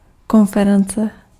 Ääntäminen
Synonyymit entretien Ääntäminen France: IPA: [kɔ̃.fe.ʁɑ̃s] Haettu sana löytyi näillä lähdekielillä: ranska Käännös Ääninäyte Substantiivit 1. přednáška {f} 2. konference {f} Suku: f .